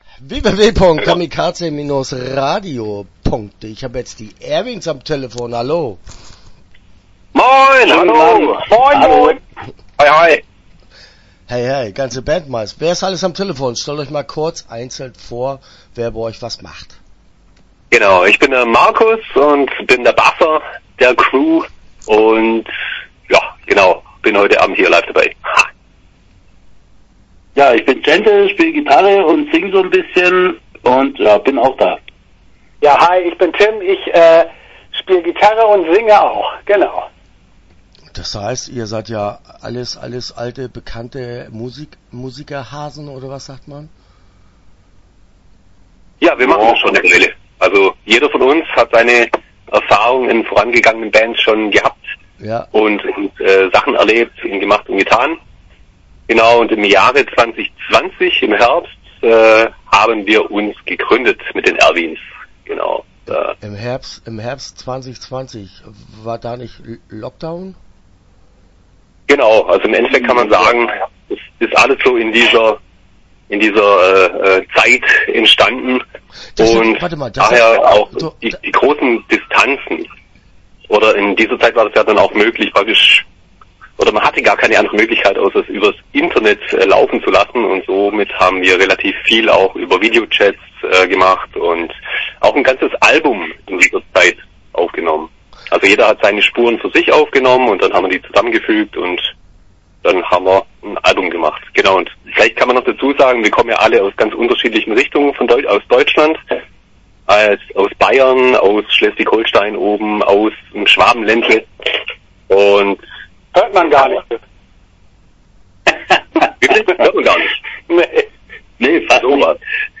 Die Erwins - Interview Teil 1 (12:46)